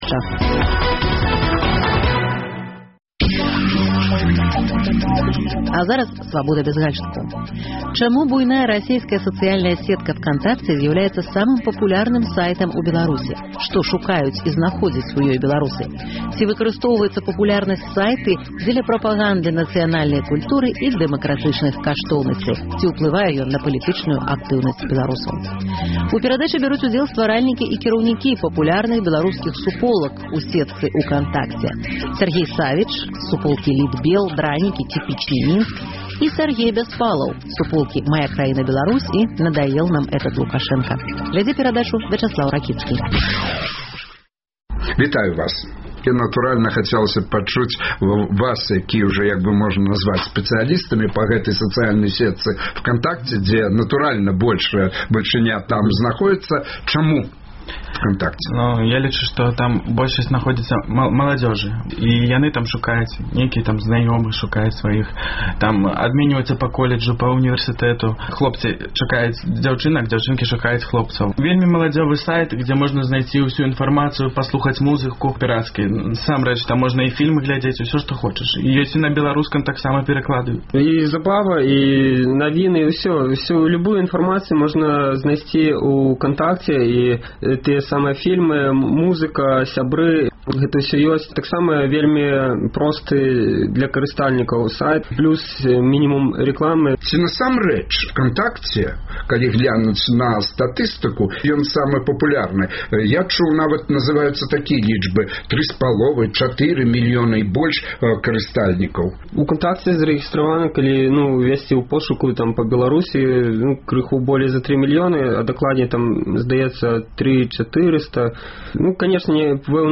Чаму буйная расейская сацыяльная сетка «Вконтакте» зьяўляецца самым папулярным сайтам у Беларусі? Што шукаюць і знаходзяць у ёй беларусы? У перадачы бяруць удзел стваральнікі і кіраўнікі папулярных беларускіх суполак у сетцы «Укантакце»